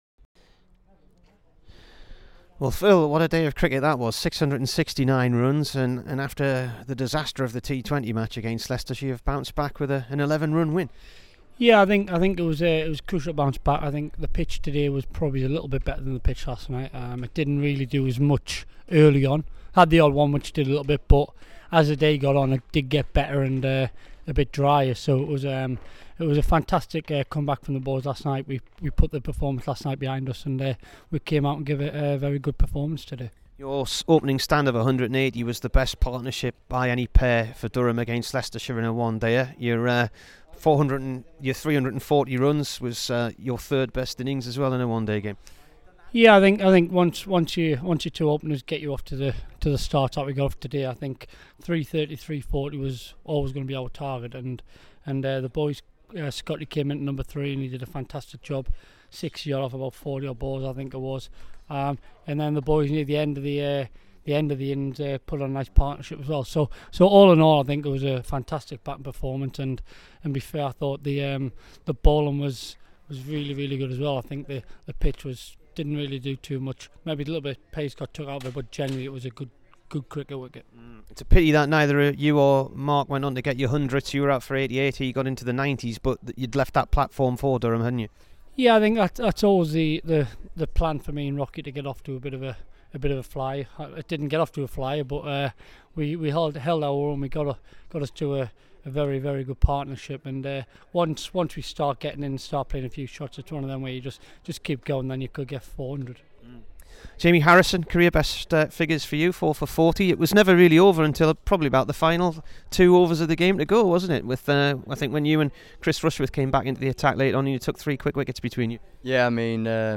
after Durham's RL Cup win at Leicester.